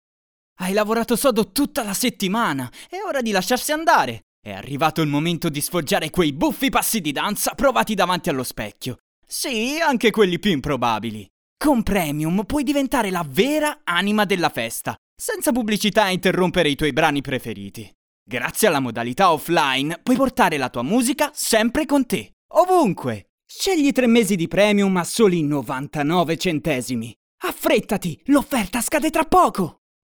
Dubber, speaker, italian voiceover, commercials, corporate, E-learning, animations, jingle, singer, games, youtube videos, documentaries,
Sprechprobe: Werbung (Muttersprache):